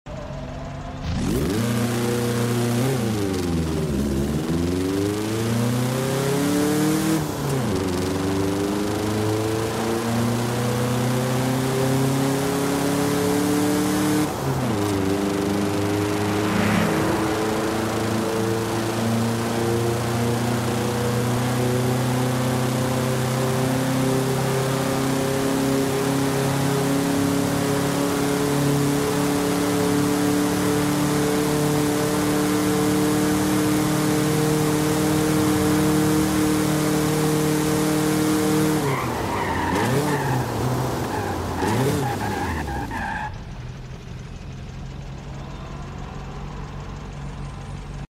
1976 Volkswagen SP 2 Launch Control sound effects free download
1976 Volkswagen SP-2 Launch Control & Sound - Forza Horizon 5